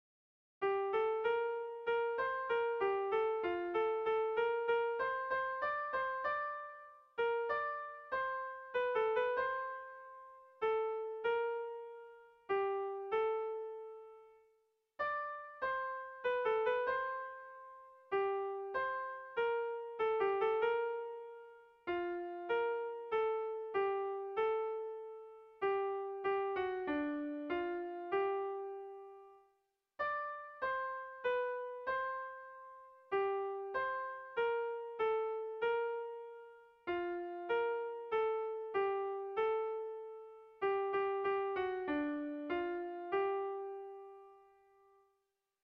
Erlijiozkoa
Seiko handia (hg) / Hiru puntuko handia (ip)
ABD